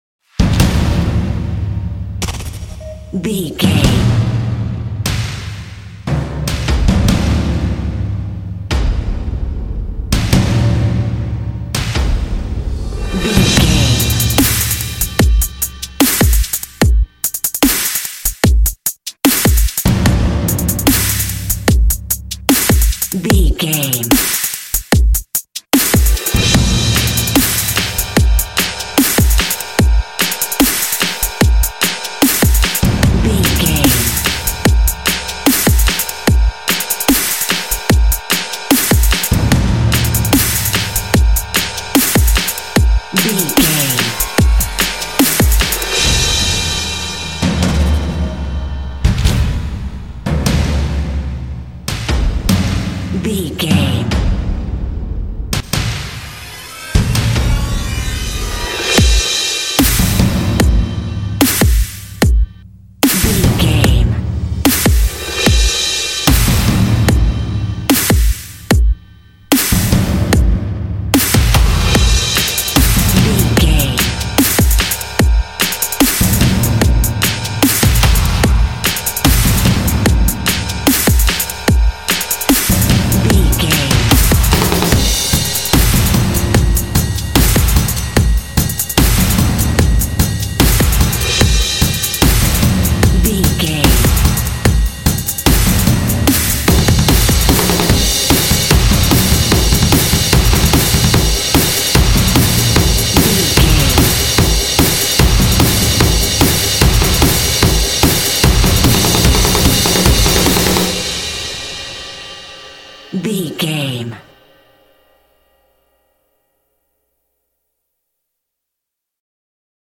Epic / Action
In-crescendo
Uplifting
Aeolian/Minor
driving
energetic
drum machine
strings
piano
percussion
cinematic
orchestral
contemporary underscore